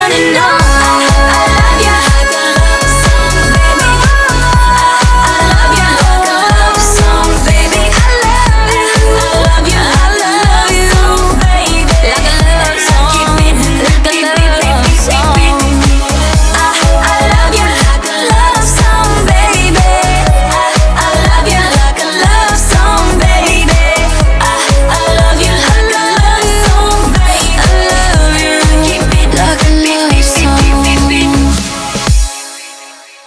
поп
веселые
энергичные
house